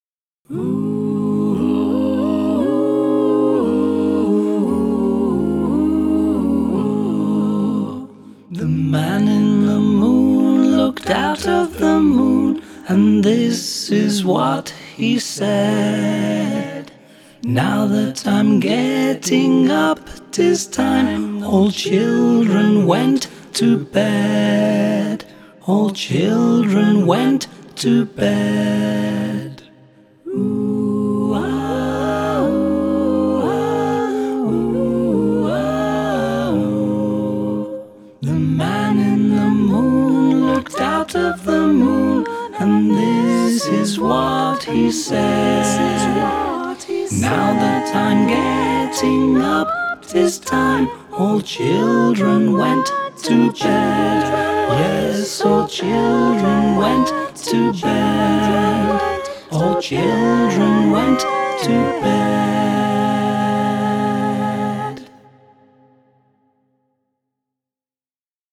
Lullaby